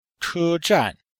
S1-L9-che1zhan4.mp3